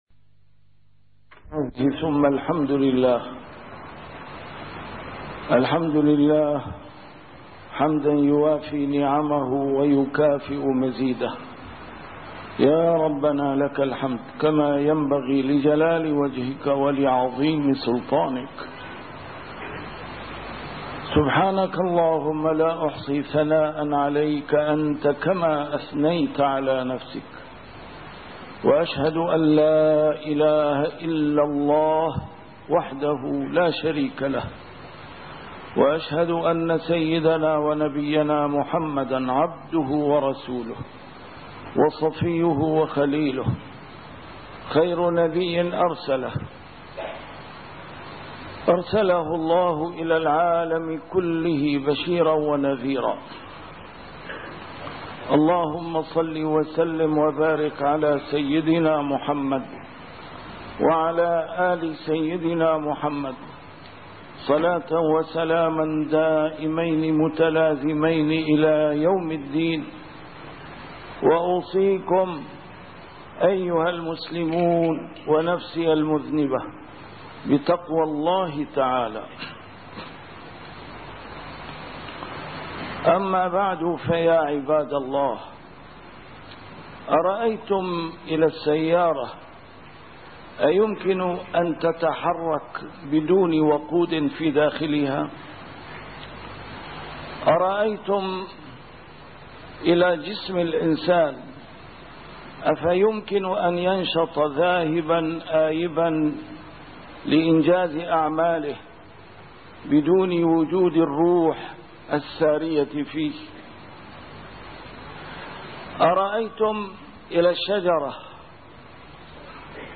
A MARTYR SCHOLAR: IMAM MUHAMMAD SAEED RAMADAN AL-BOUTI - الخطب - سر الفلاح المفقود